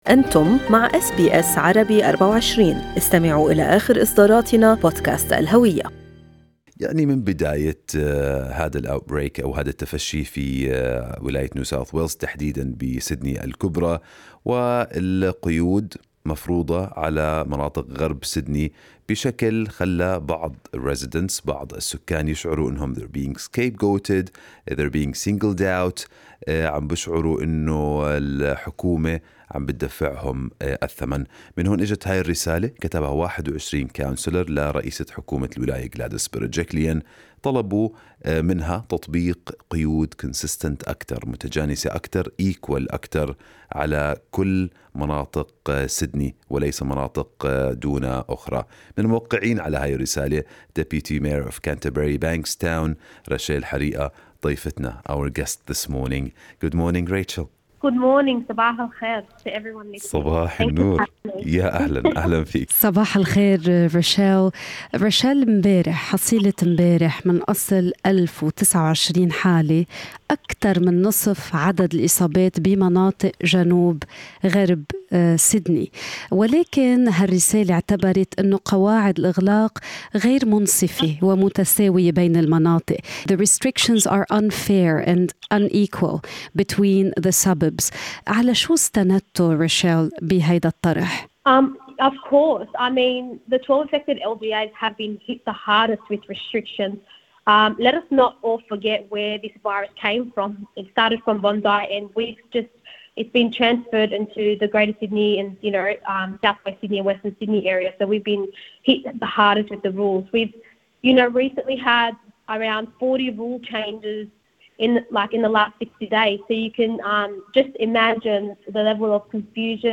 لتسليط الضوء حول الرسالة المشتركة التي تدعو للمزيد من القيود "المتجانسة" وتنفيذها بالتساوي على امتداد منطقة سيدني الكبرى، كان لبرنامج “Good morning Australia” هذا الحوار مع نائبة رئيس بلدية Canterbury-Bankstown، راشيل حريقة.